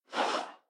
Swish.mp3